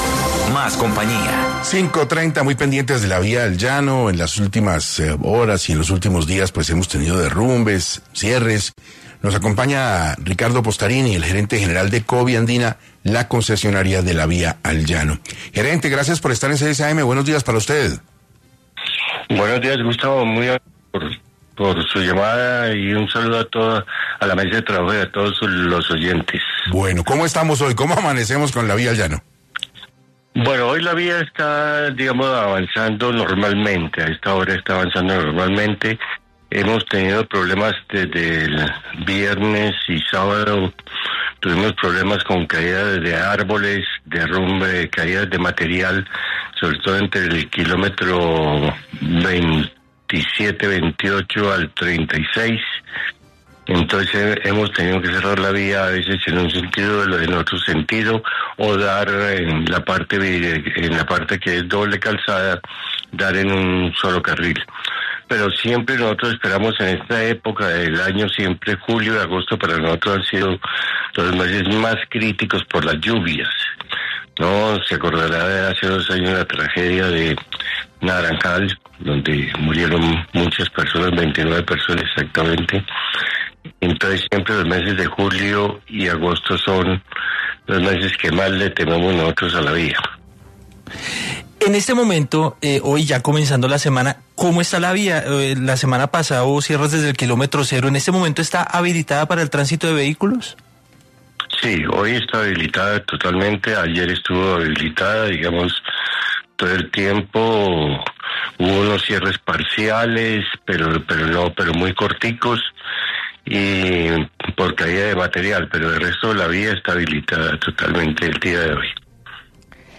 Radio en vivo